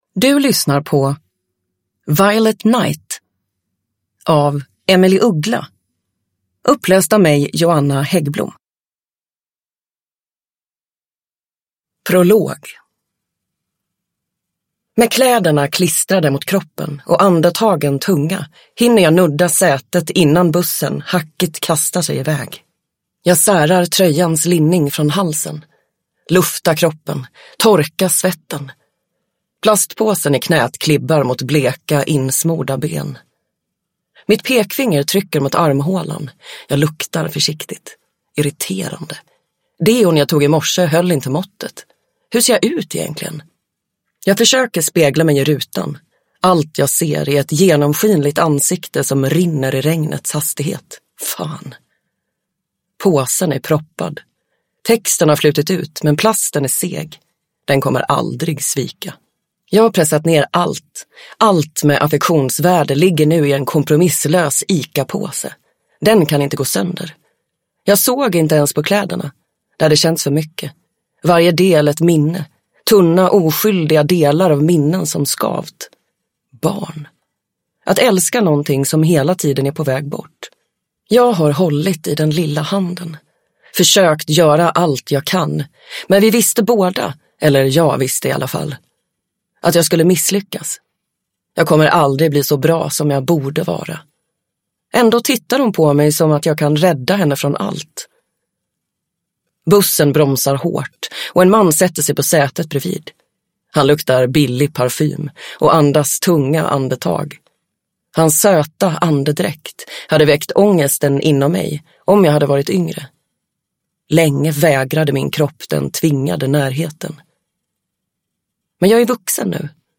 Violet night (ljudbok) av Emelie Uggla | Bokon